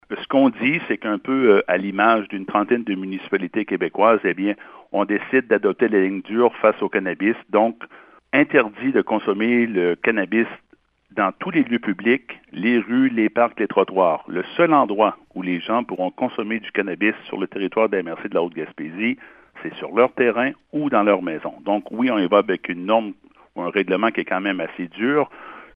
Le préfet, Allen Cormier, explique le choix  des élus.